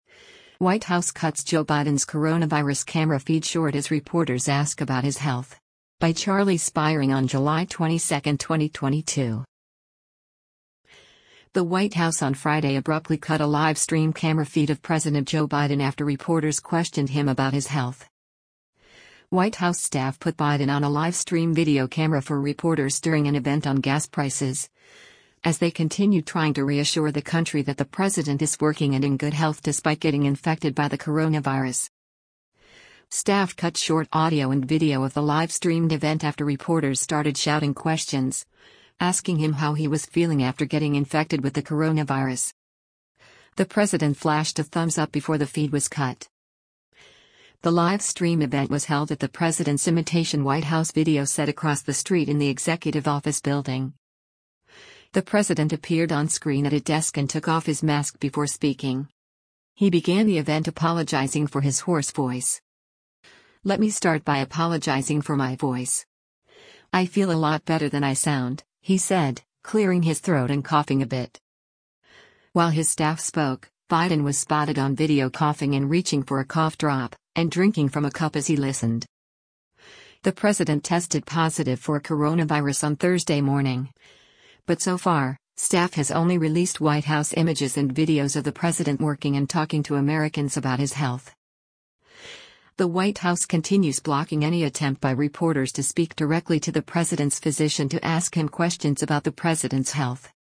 The White House on Friday abruptly cut a live stream camera feed of President Joe Biden after reporters questioned him about his health.
Staff cut short audio and video of the live-streamed event after reporters started shouting questions, asking him how he was feeling after getting infected with the coronavirus.
He began the event apologizing for his hoarse voice.
“Let me start by apologizing for my voice. I feel a lot better than I sound,” he said, clearing his throat and coughing a bit.